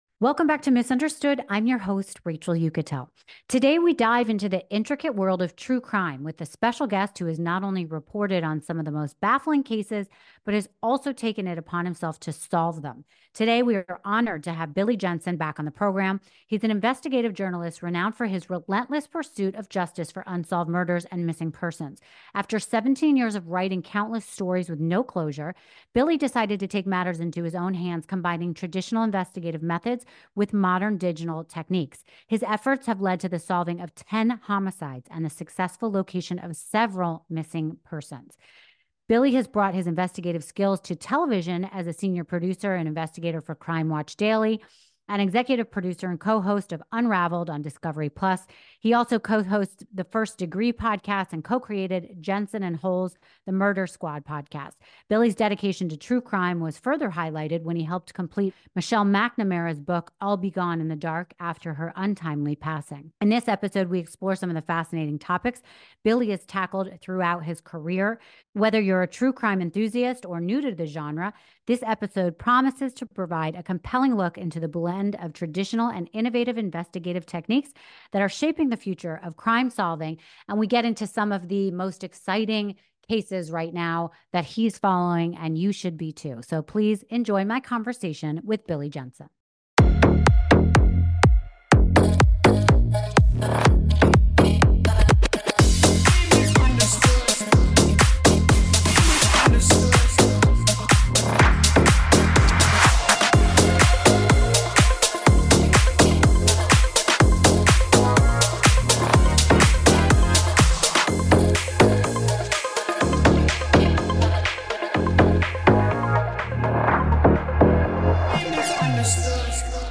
Don’t miss this compelling conversation with a true crime expert dedicated to justice.